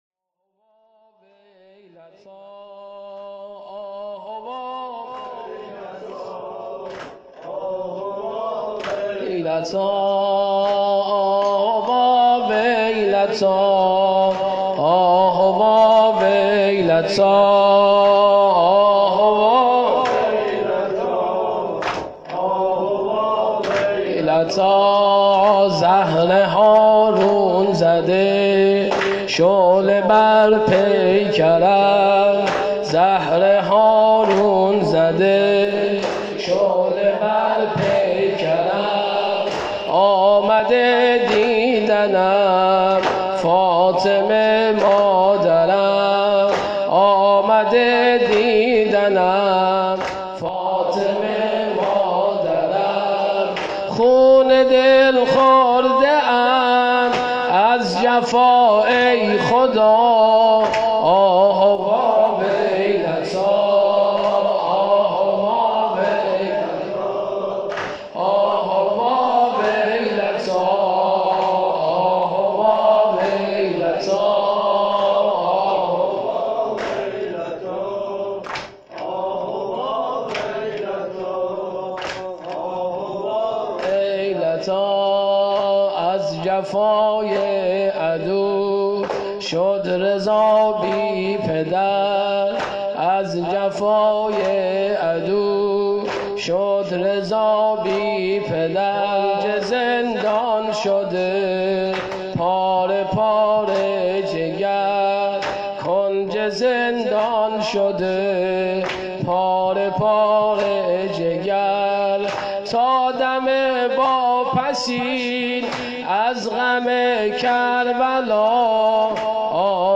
هیأت زوارالزهرا سلام اللّه علیها
شهادت امام موسی کاظم97